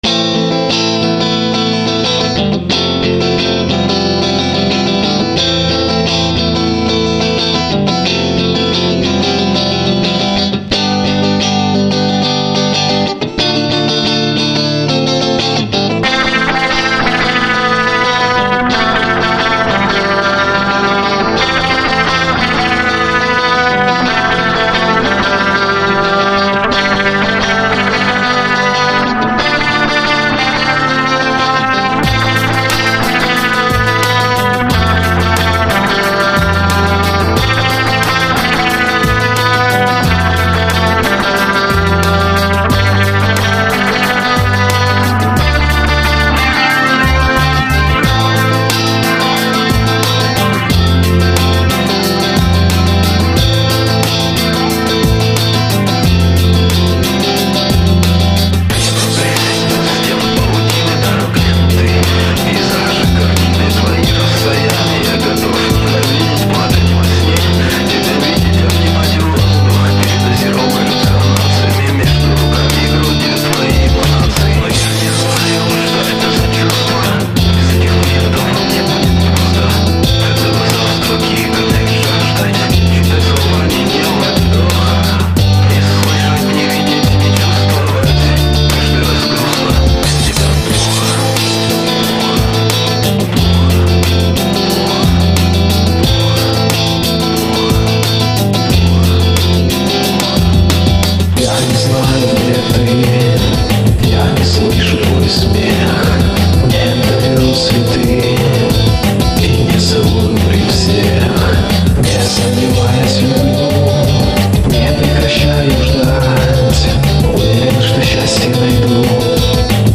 • Жанр: Поп
это предварительный набросок на очень грустную песню..